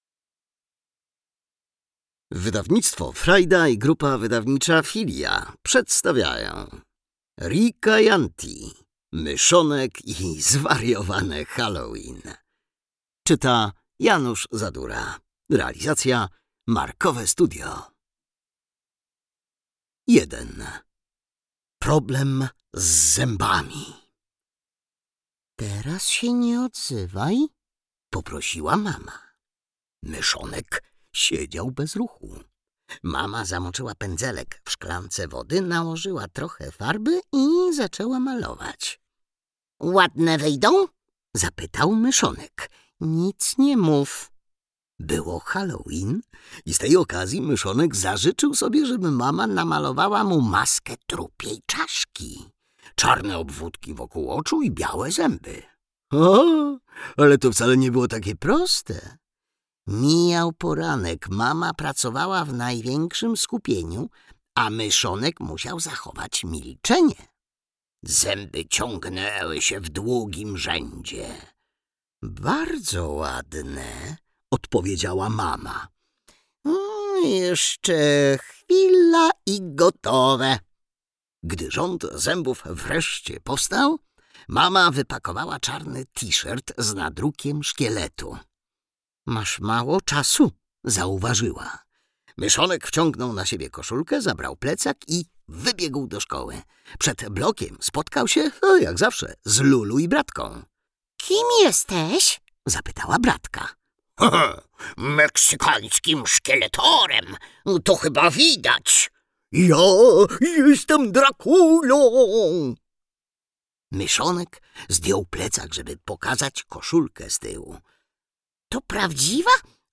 Audiobook + książka Myszonek i zwariowane Halloween, Riikka Jäntti.